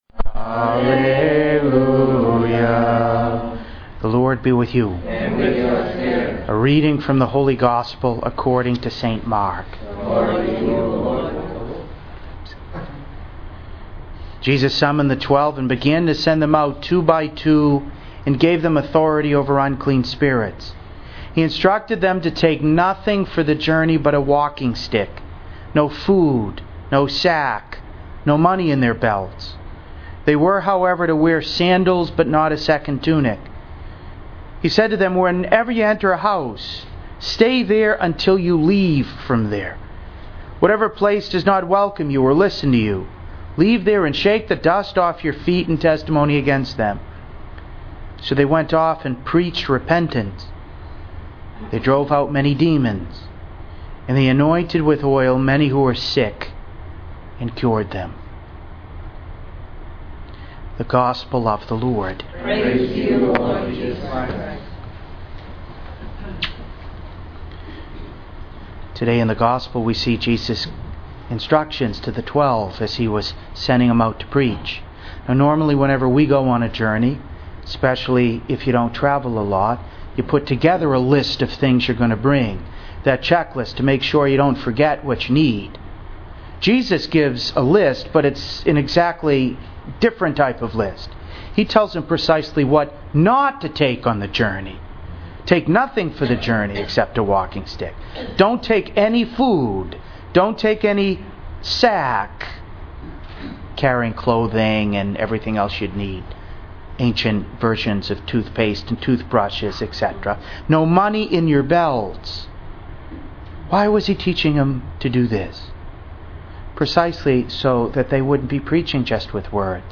To listen to an audio recording of today’s homily, please click below:
2.6.14-Homily-1.mp3